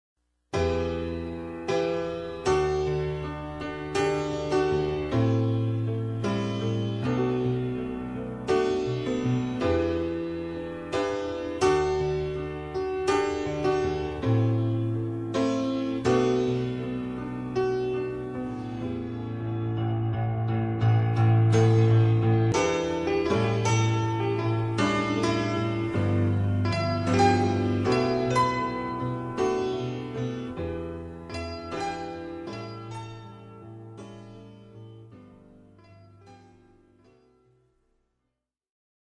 [ "rock" ]